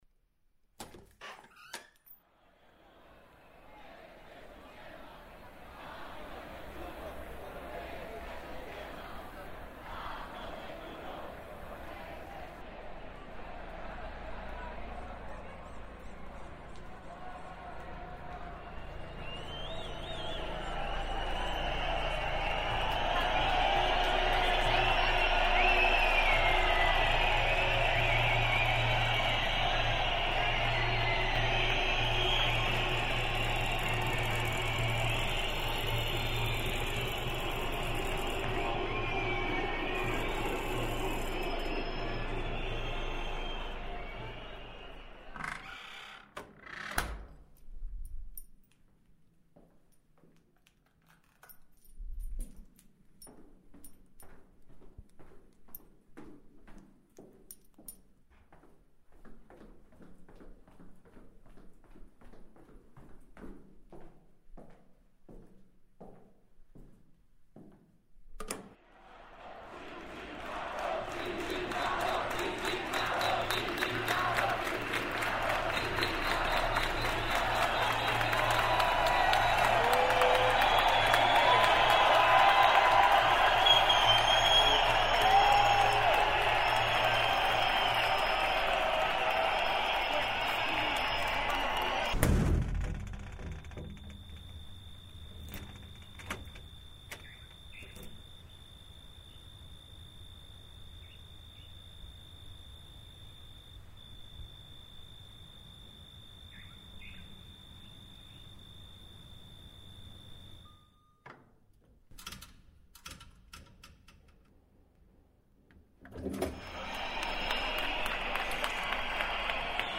Madrid protest reimagined